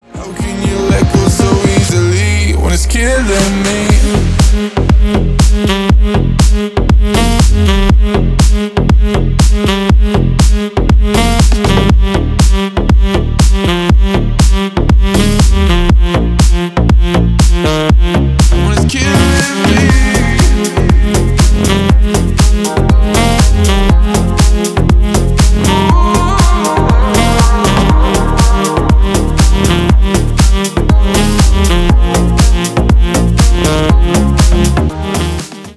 Поп Музыка # Танцевальные
клубные